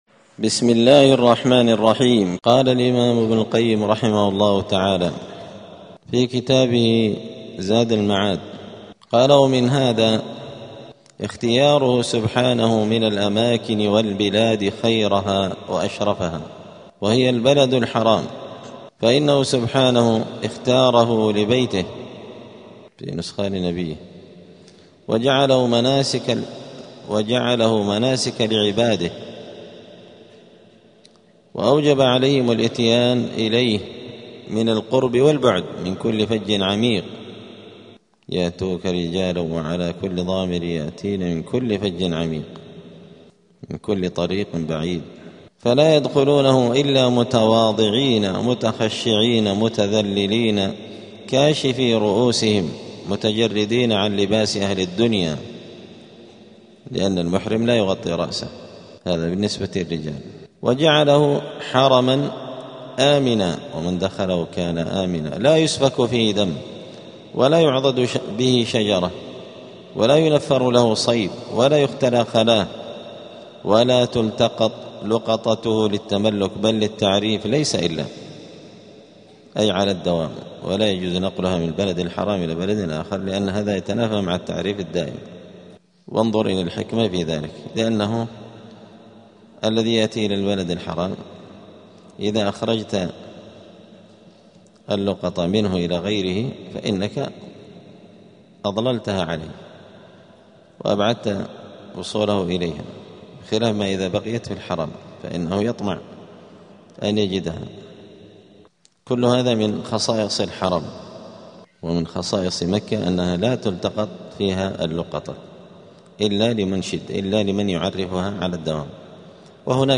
*الدرس الخامس (5) {ﻓﺼﻞ ﻓﻲ ﺫﻛﺮ ﻣﺎ اﺧﺘﺎﺭ اﻟﻠﻪ ﻣﻦ ﻣﺨﻠﻮﻗﺎﺗﻪ}.*
دار الحديث السلفية بمسجد الفرقان قشن المهرة اليمن